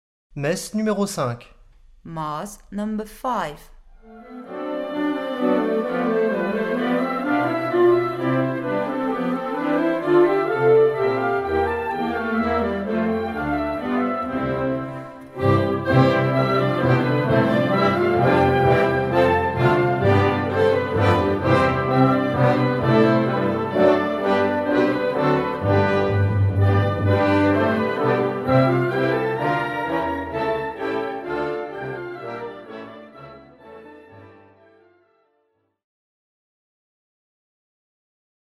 Répertoire pour Harmonie/fanfare